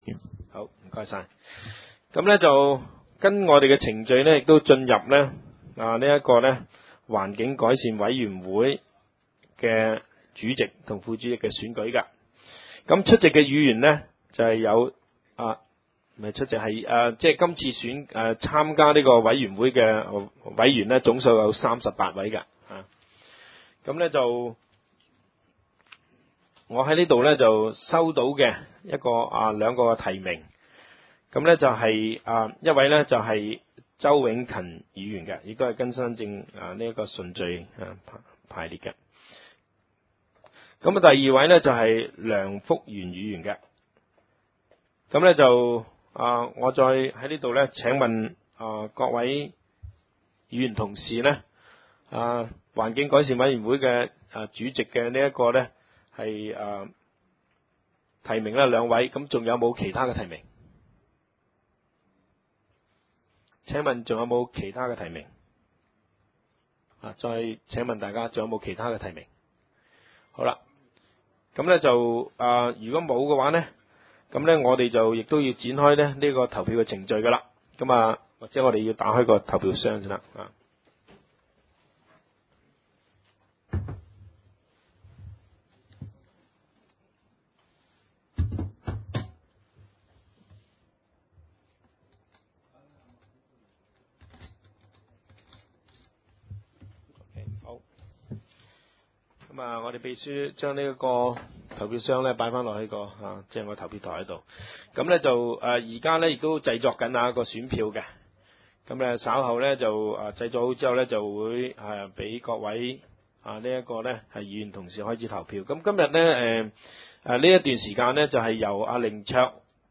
地 點： 元朗橋樂坊 2 號元朗政府合署十三樓會議廳 議 程 討論時間 一． 選舉環境改善委員會主席及副主席 16分 二． 其他事項 *********************************************